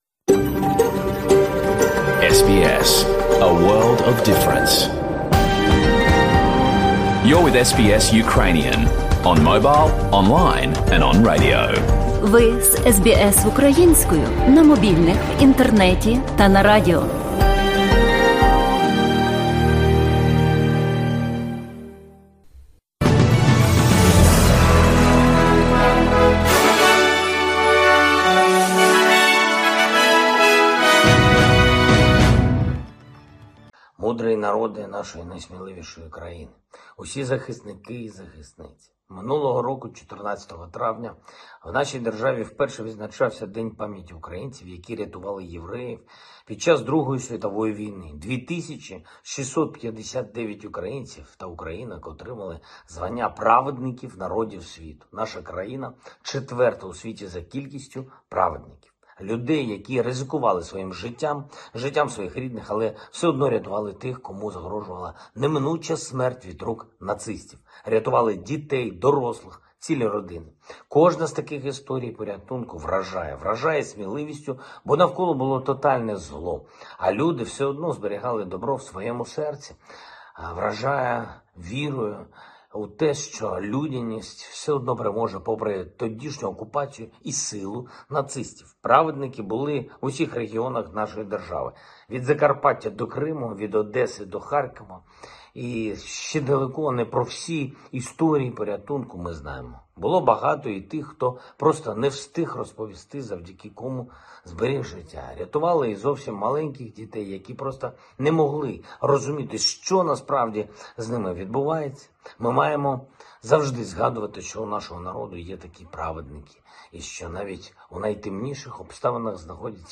Звернення Президента України Володимира Зеленського